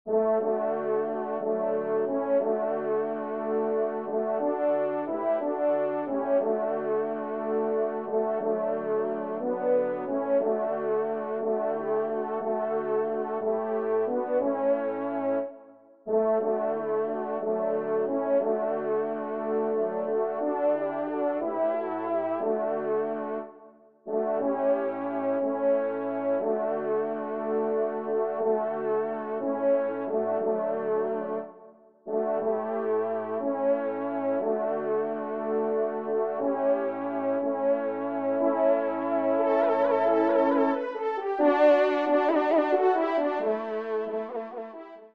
Pupitre 3° trompe (en exergue)